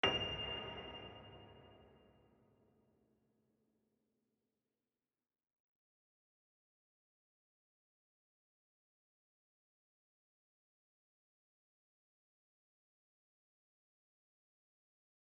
GreatAndSoftPiano